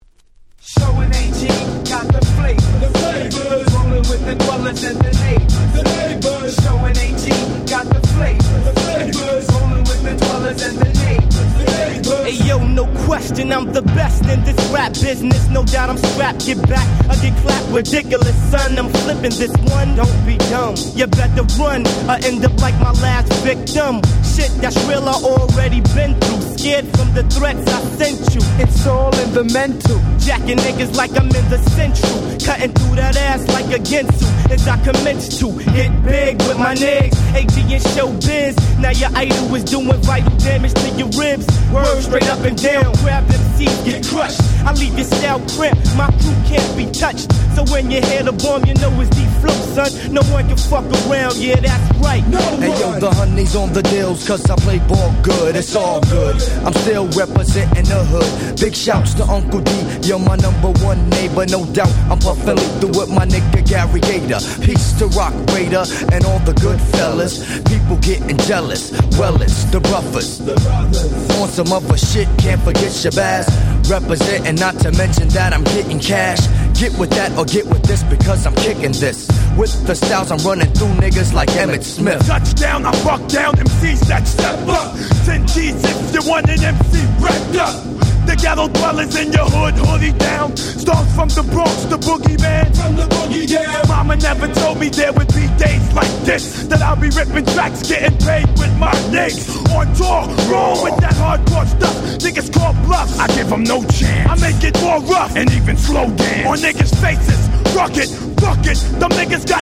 95' Very Nice Hip Hop !!